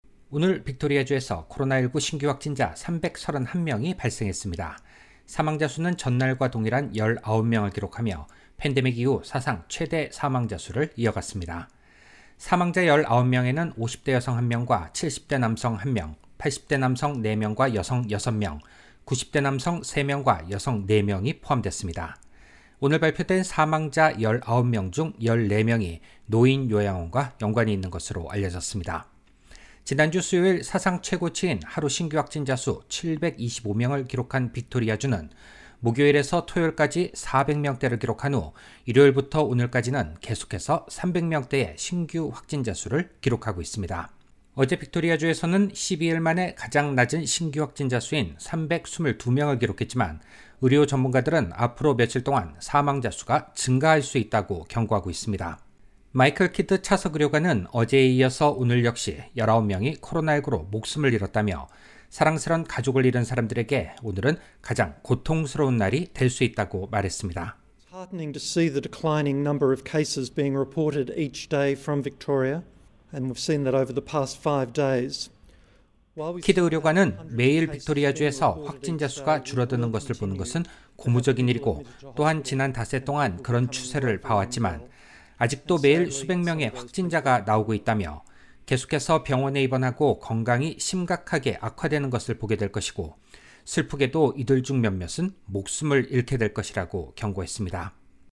virus_vic_11_08_korean_audio_news.mp3